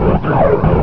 player_dead1.ogg